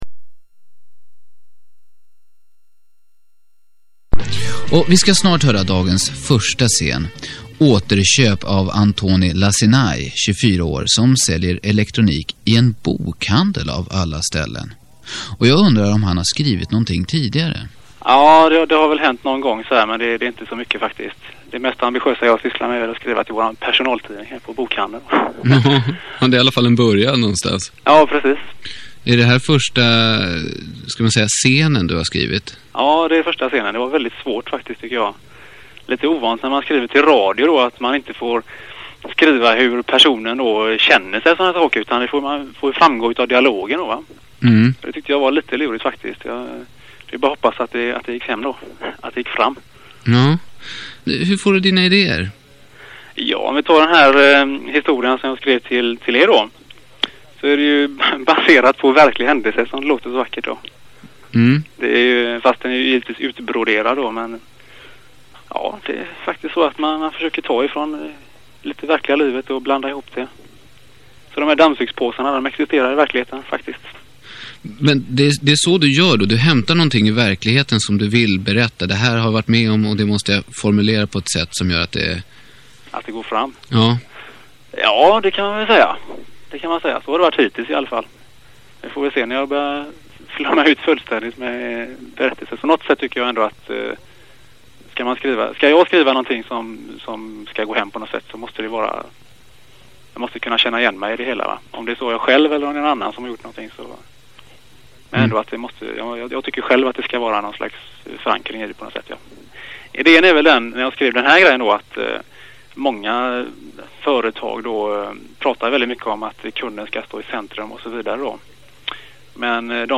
Aterkop-Radioteater-fran-1993
Aterkop-Radioteater-fran-1993.mp3